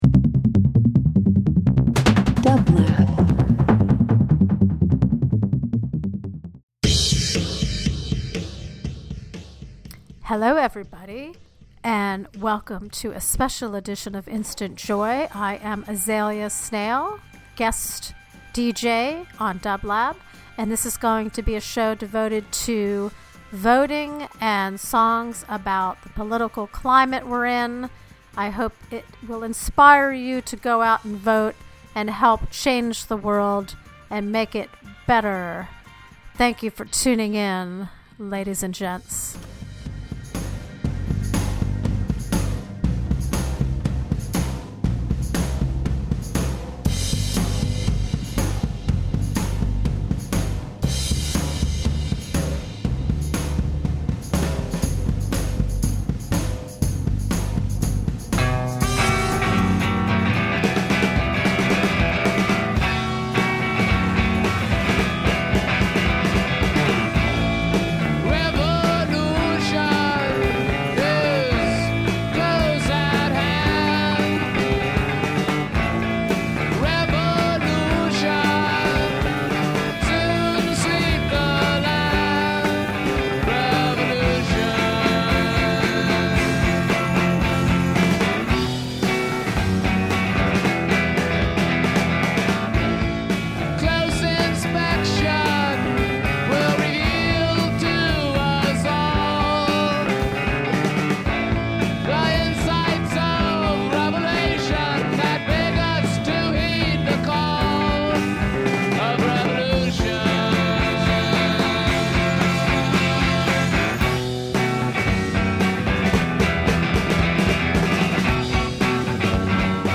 Alternative Indie Rock